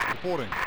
Alright, I made two English infrantry sounds.
1st time I made a sound effect. :o